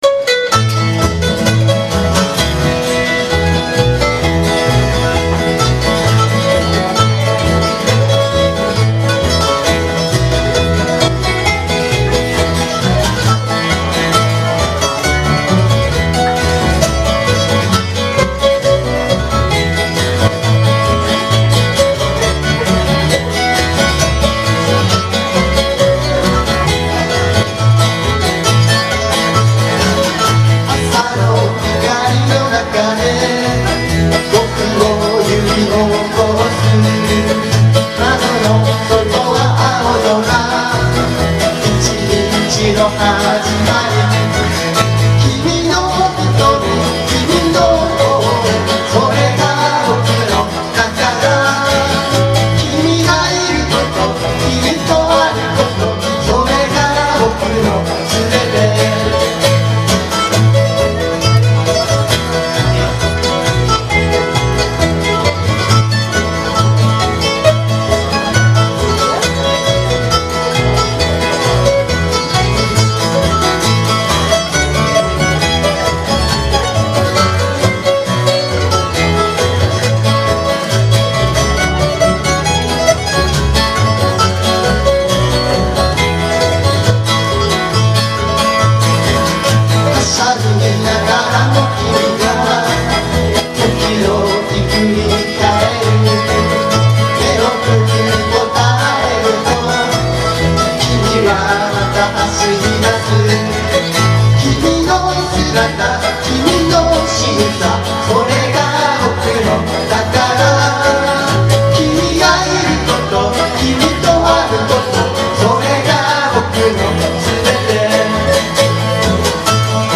Bluegrass style Folk group
Key of A
マンドリンがもっとも得意とするAのキーで演奏しています。
企画名: SPECIAL LIVE!! Vol.17
録音場所: みずほ台Paraiso
ボーカル、マンドリン
ボーカル、ギター
ベース